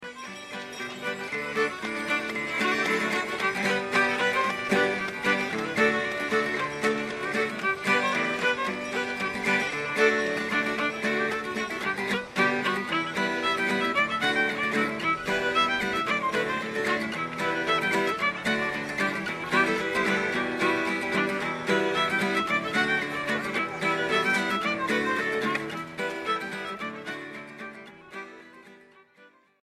A part including repeats:
B part including repeats: